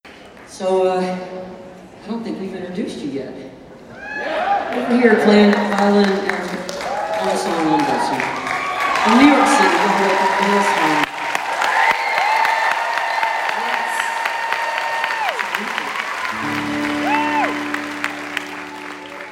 04. talking with the crowd (0:19)